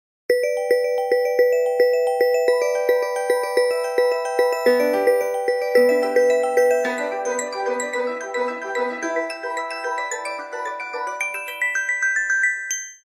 Categoria Telefone